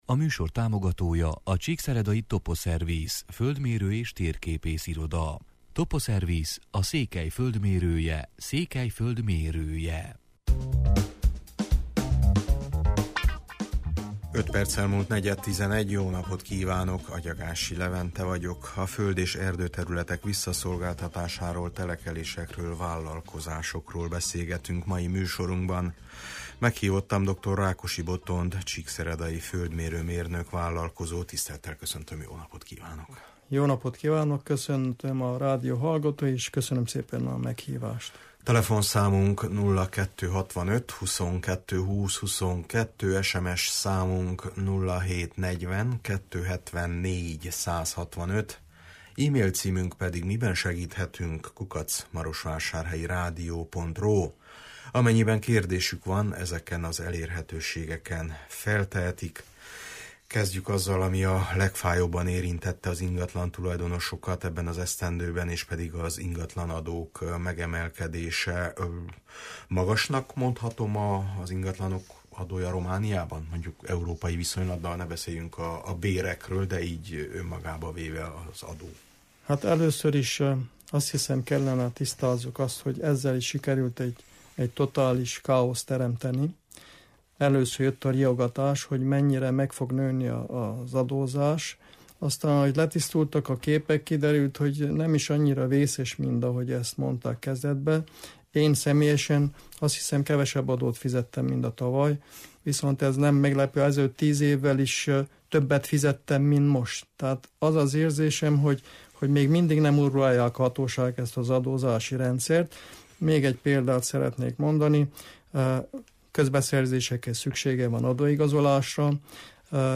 Az föld- és erdőterületek visszaszolgáltatásáról, ezek piacáról, telekelésekről, vállalkozásokról beszélgetünk mai műsorunkban.